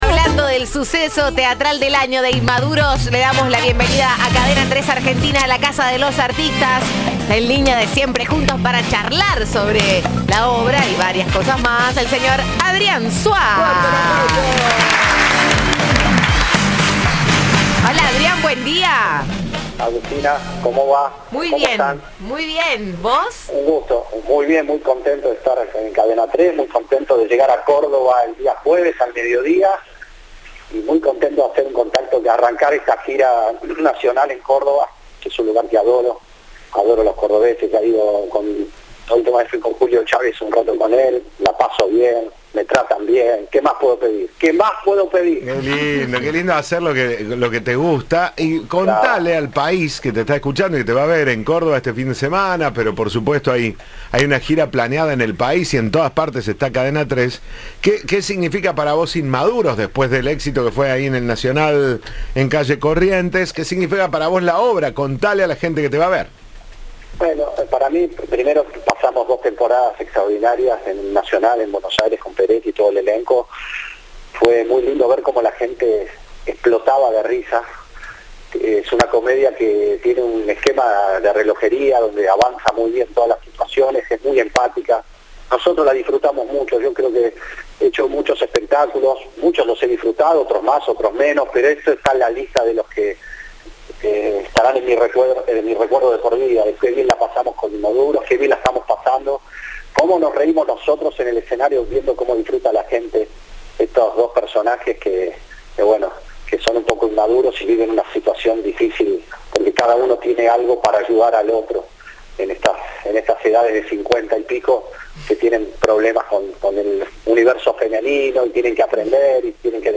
Entrevista de "Siempre Juntos".